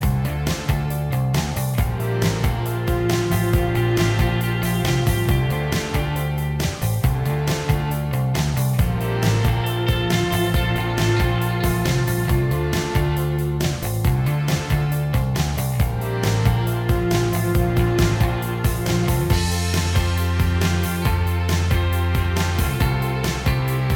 Minus Lead Guitar Indie / Alternative 4:30 Buy £1.50